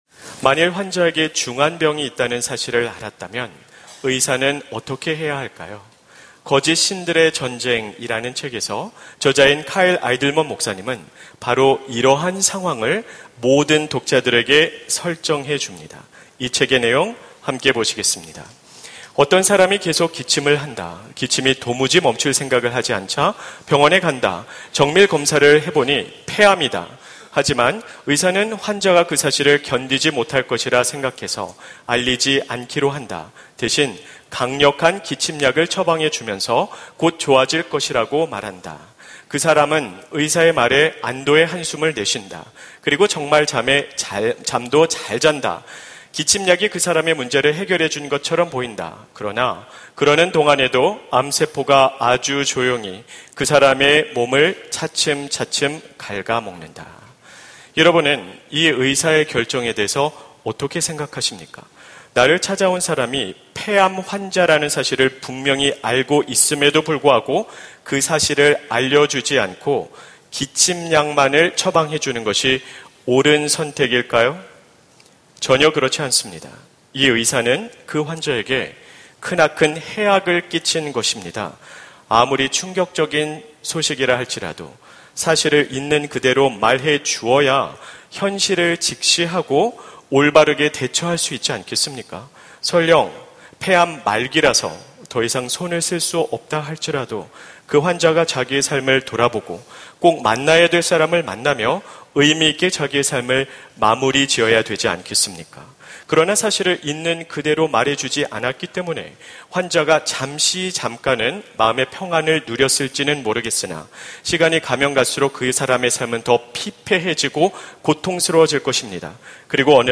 설교 : 주일예배 예레미야 - 복음수업 8 : 예수님은 그렇게 말하지 않았습니다! 설교본문 : 마태복음 7:13-14